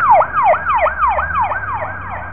cop-car.wav